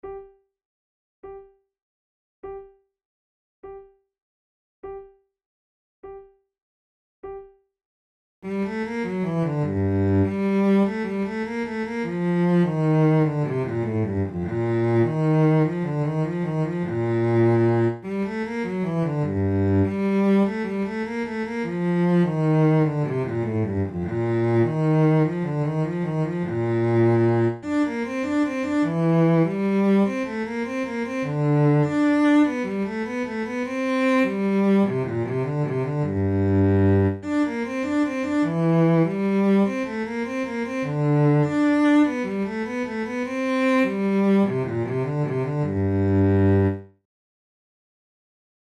InstrumentationFlute and bass instrument
KeyG minor
Time signature2/2
Tempo100 BPM
Baroque, Gavottes, Sonatas, Written for Flute